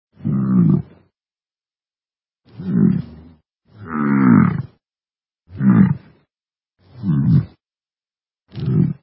bison
bison.mp3